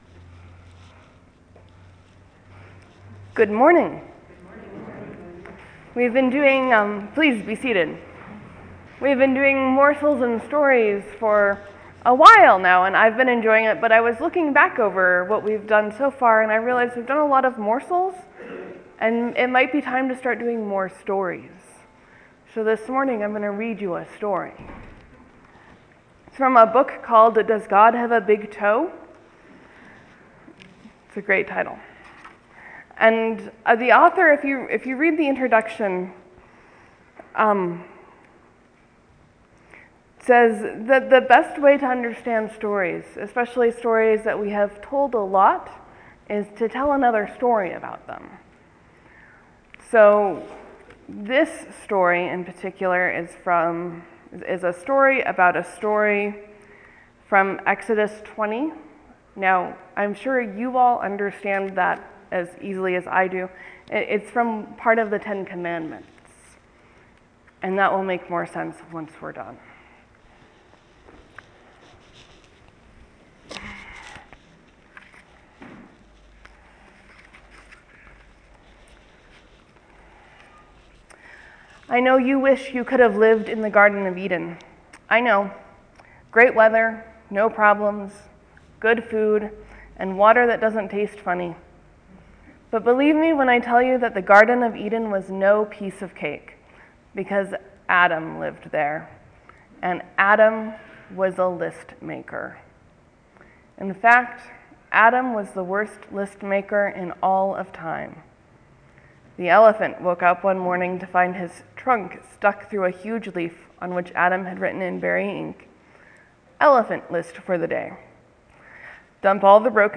Sermon: Jesus inserts himself into a very old story, one about God and bread.